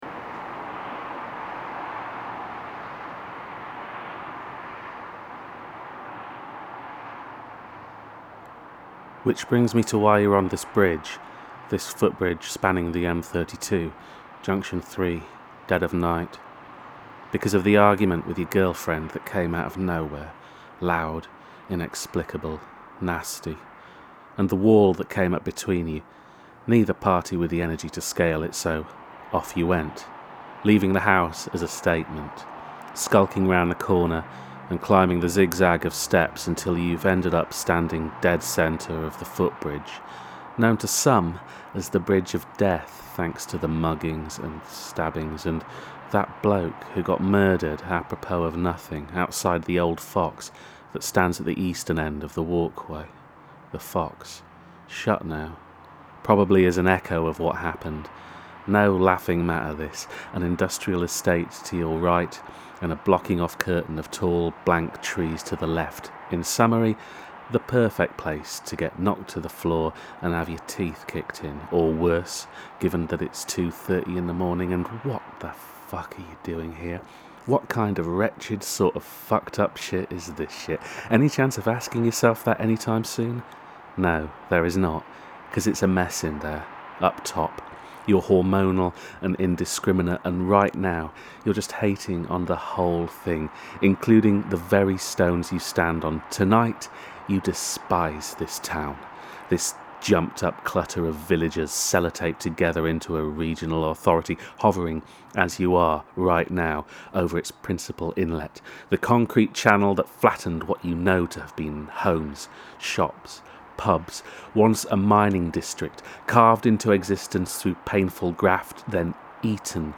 A short play
Quiet, still and a little embarrassed by its own poetry, this is a portrait of Bristol past, present and future – seen through a drunken haze on an unexpectedly clear night.